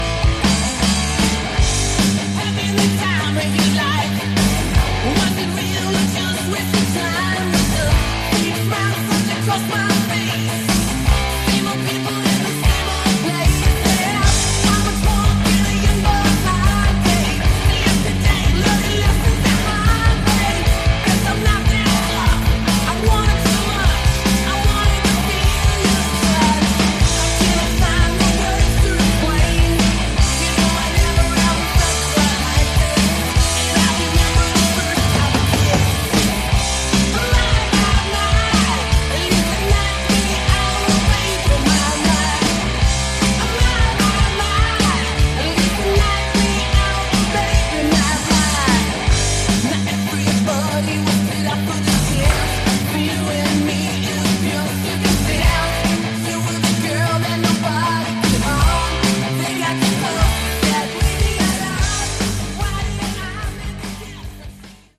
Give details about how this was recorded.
Good songs, shame about the production.